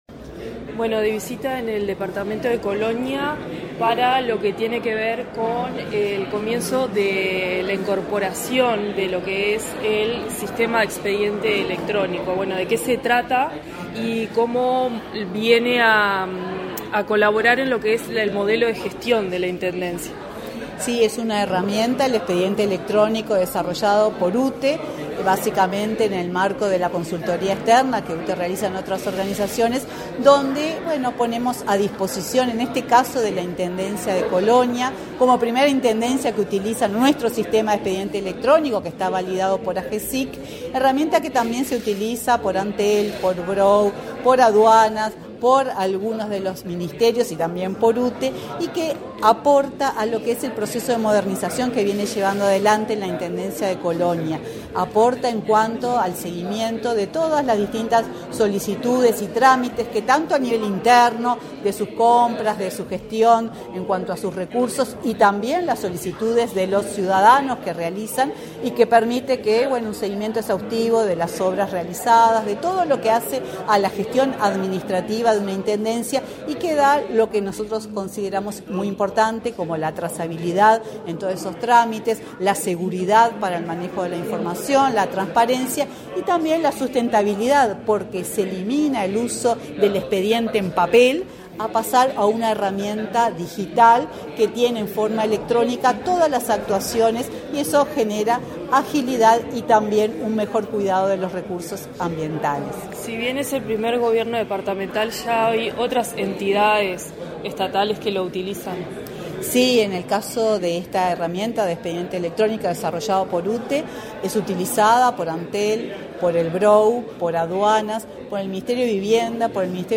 Entrevista a la presidenta de UTE, Silvia Emaldi
Entrevista a la presidenta de UTE, Silvia Emaldi 09/10/2023 Compartir Facebook X Copiar enlace WhatsApp LinkedIn La UTE presentó, este 9 de octubre, la implementación del expediente electrónico en la Intendencia de Colonia, de la cual estuvo a cargo. La presidenta de la empresa estatal, Silvia Emaldi, explicó a Comunicación Presidencial los avances que permitirá esta nueva herramienta.